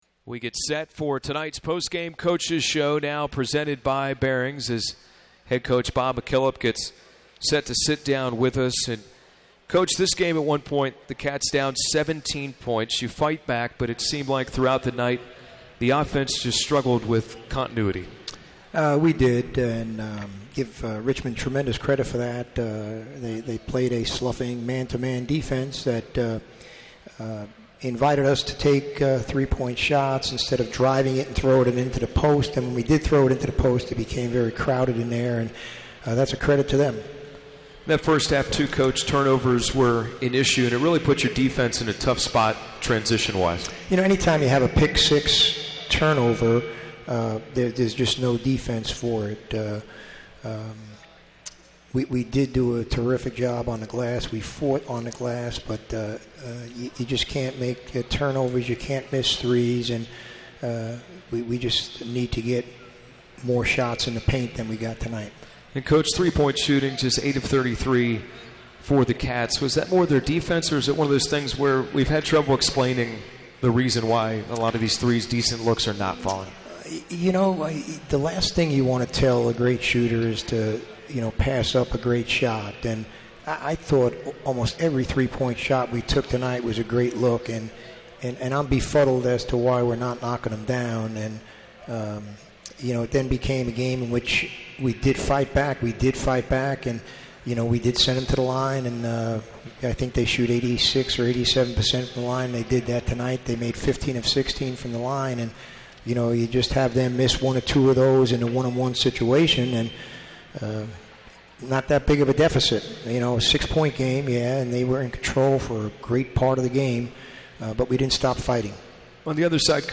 McKillop Postgame Interview